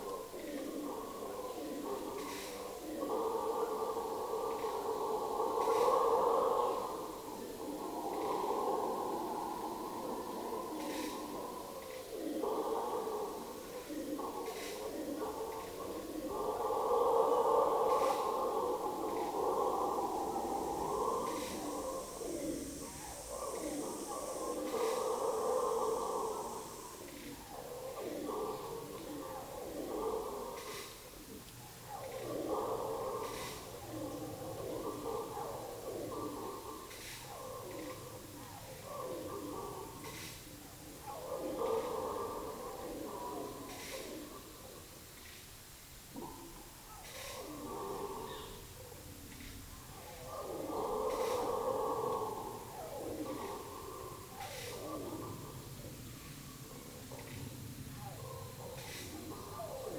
Mamíferos
(Alouatta seniculus)